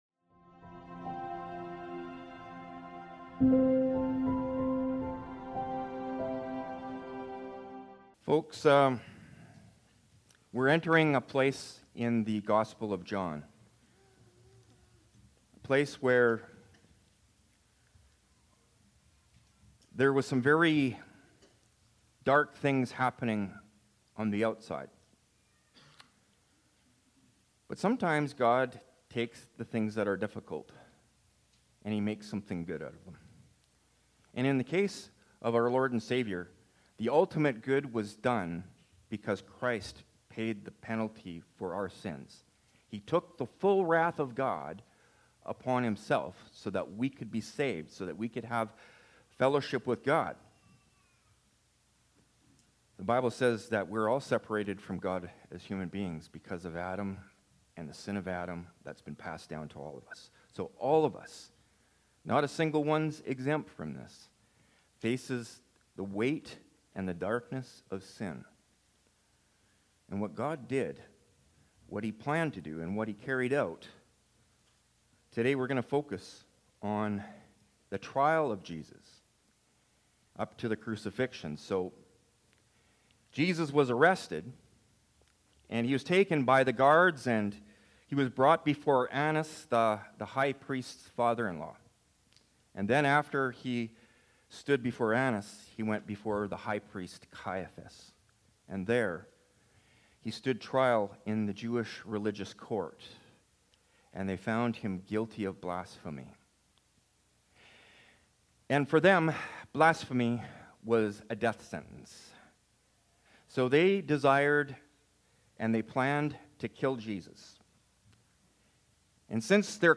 Sermons | Hillside Community Church